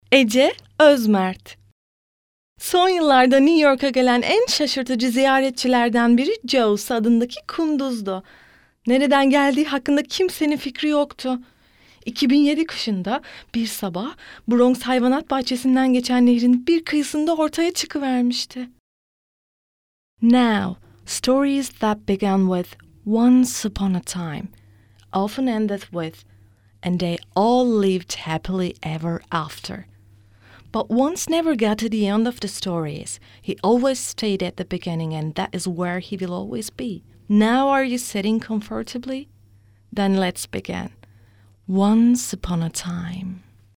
Voice over Dubbing female cartoons
Sprechprobe: Werbung (Muttersprache):